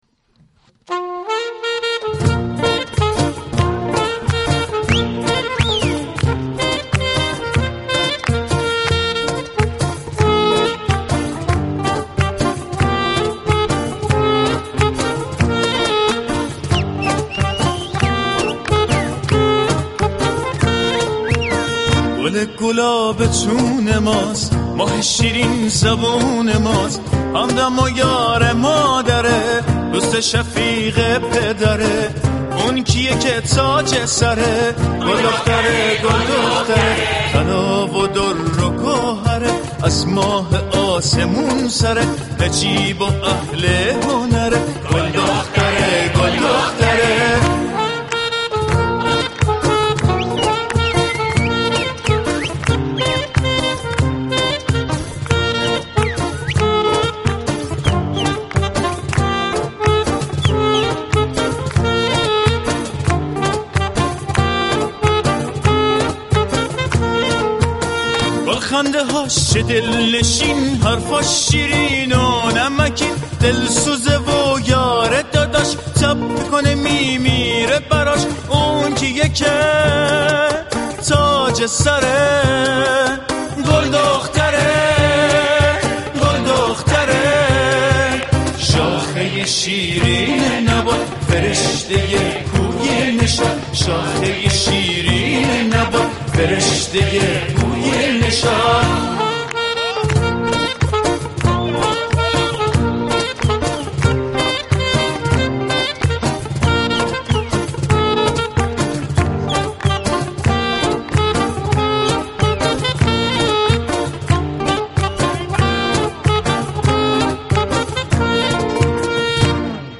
نمایش طنز نمكی اجرا می شود/ برنامه ای ویژه دختران